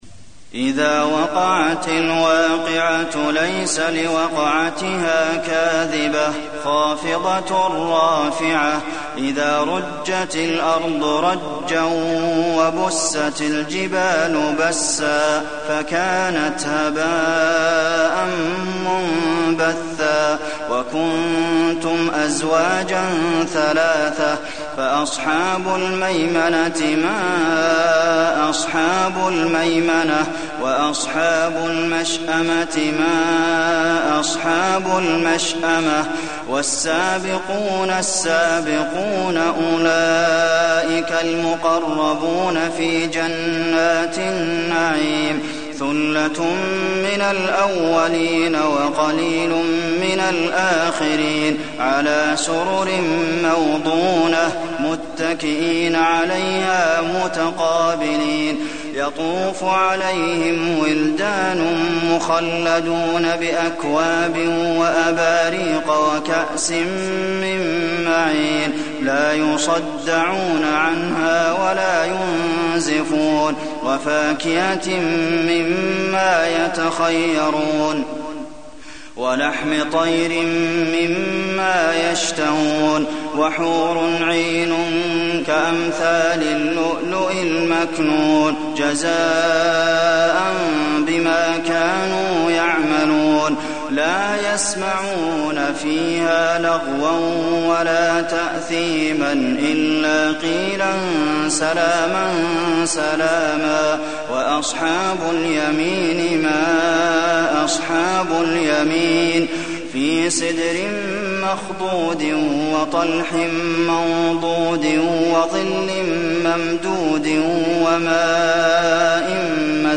المكان: المسجد النبوي الواقعة